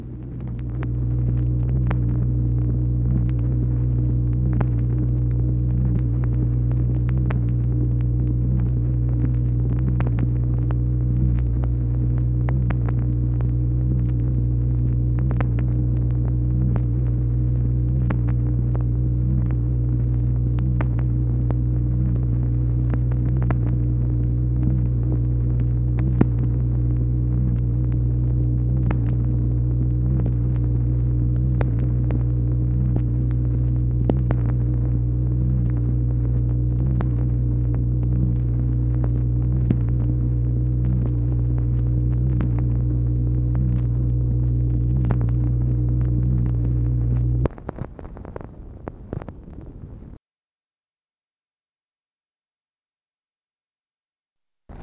MACHINE NOISE, January 1, 1964
Secret White House Tapes | Lyndon B. Johnson Presidency